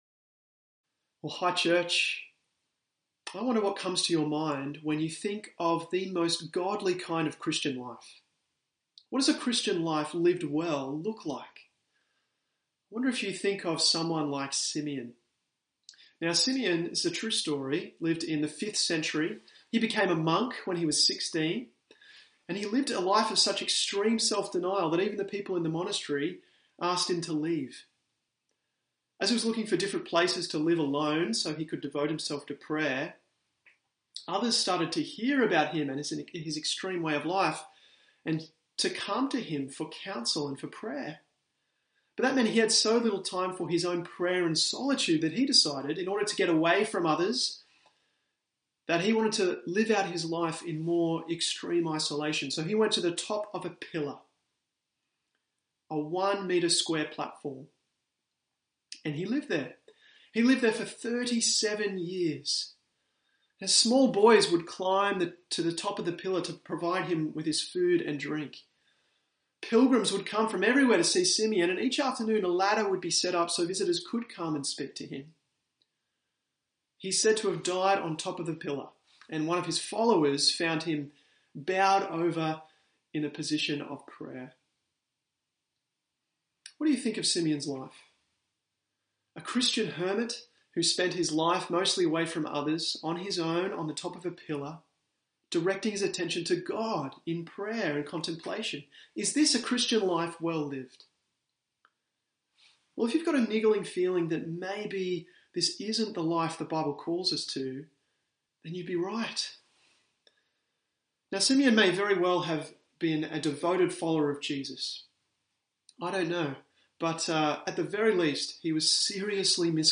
Click the ‘Download Sermon’ button or press ‘play’ in the audio bar above for an audio-only version of the sermon.